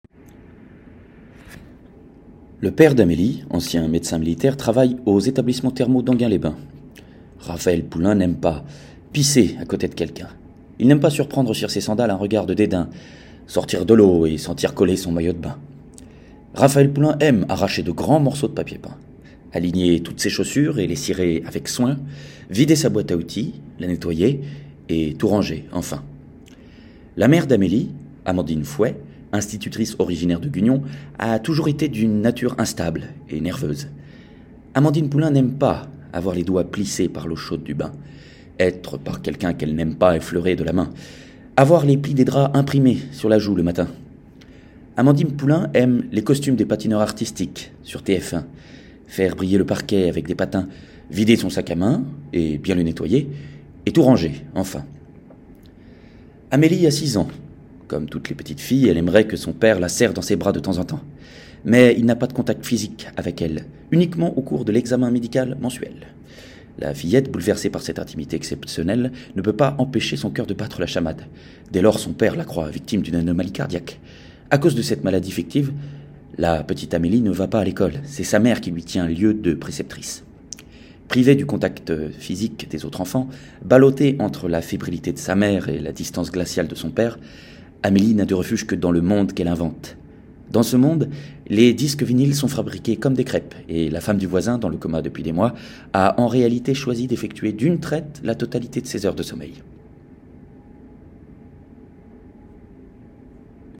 Voix off
- Baryton